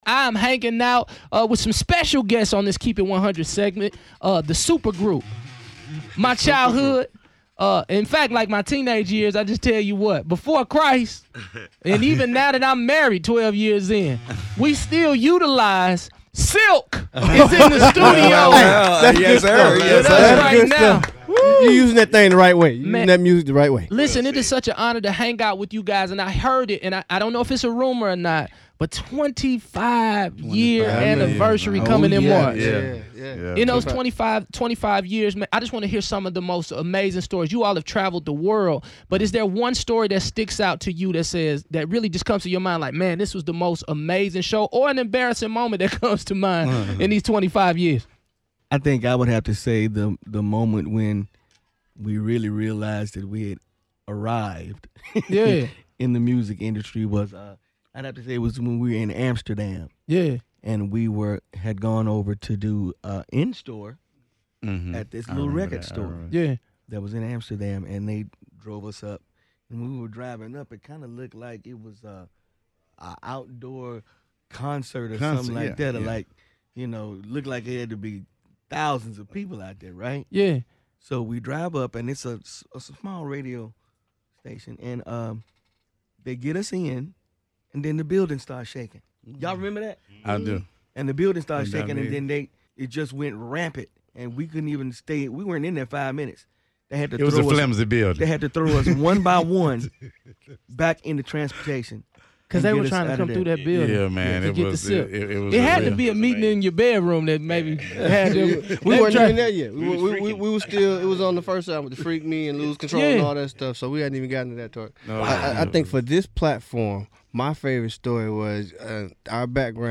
The band SILK stopped by to see what was good!